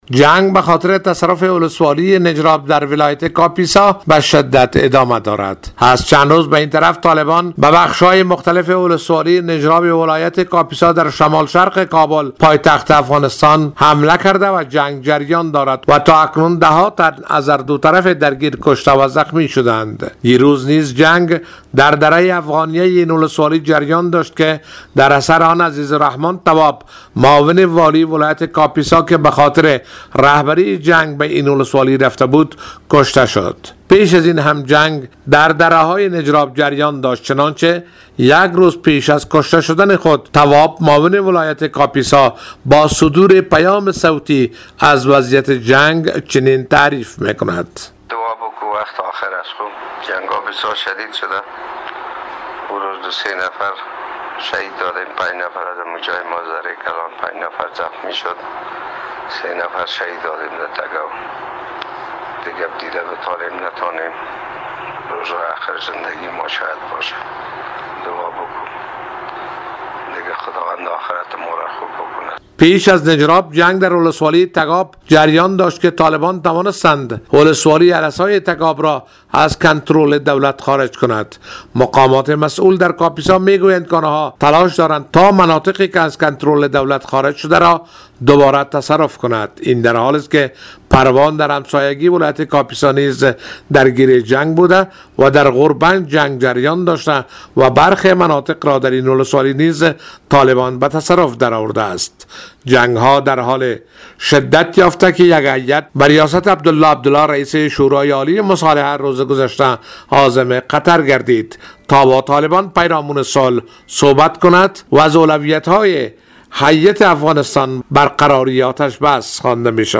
گزارش تکمیلی